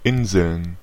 Ääntäminen
Ääntäminen Tuntematon aksentti: IPA: /ˈʔɪnzl̩n/ Haettu sana löytyi näillä lähdekielillä: saksa Käännöksiä ei löytynyt valitulle kohdekielelle. Inseln on sanan Insel monikko.